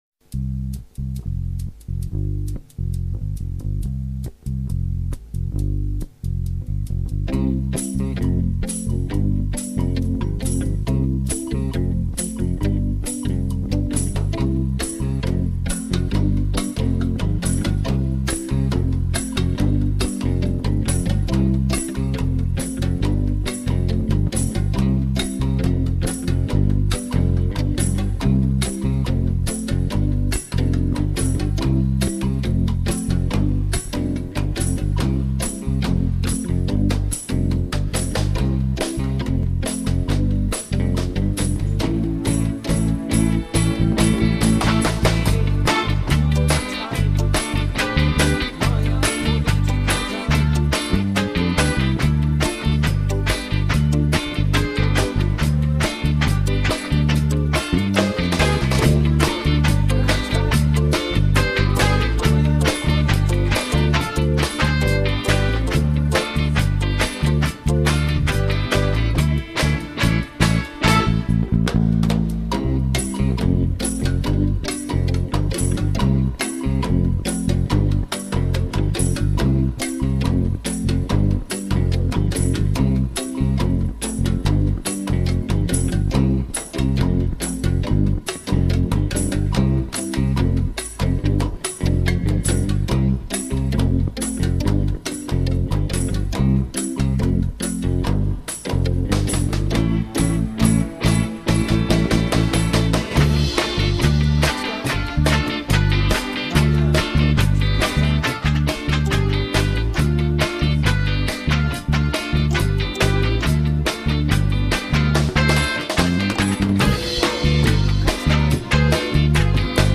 Wahlkampfsongs
auchdukaraoke.mp3